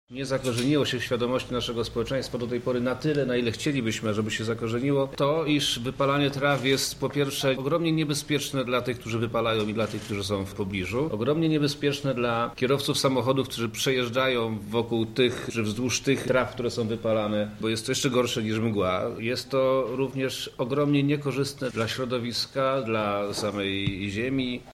– mówi Przemysław Czarnek, Wojewoda Lubelski.